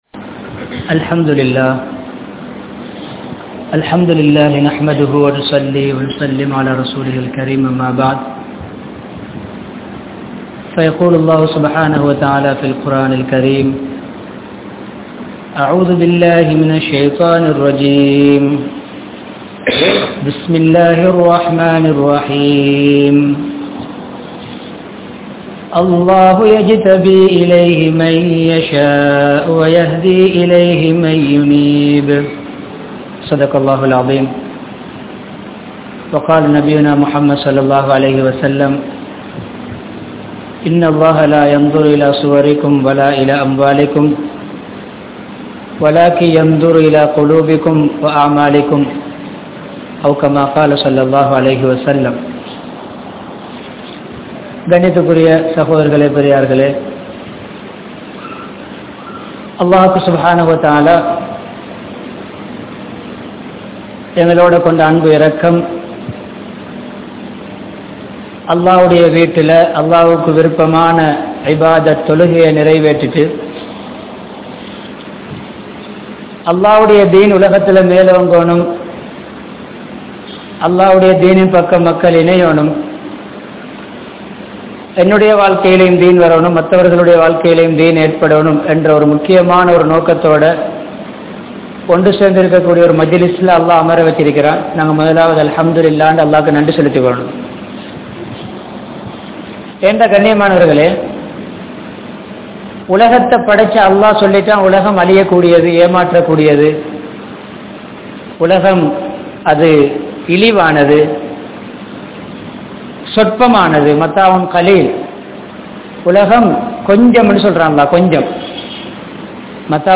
Ullangalai Paarpavan Allah (உள்ளங்களை பார்ப்பவன் அல்லாஹ்) | Audio Bayans | All Ceylon Muslim Youth Community | Addalaichenai